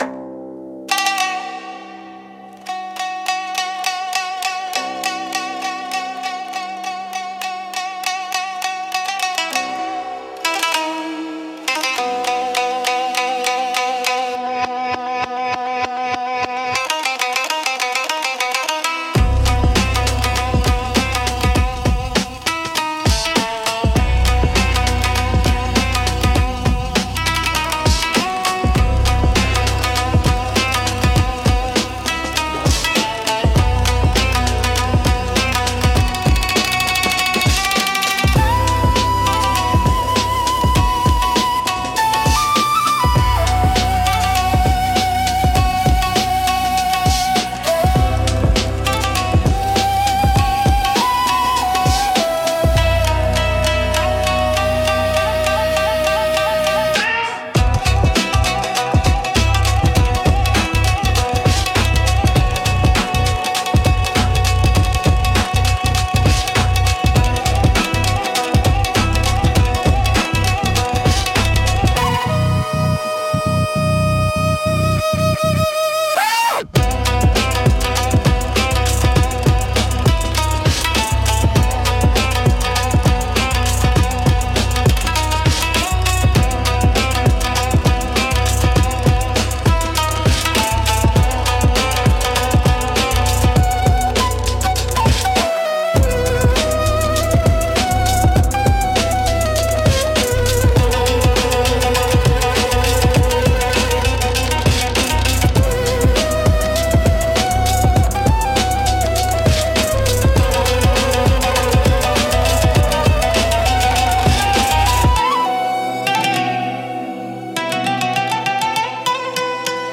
Experiment_JapanesetripJerseyclub.mp3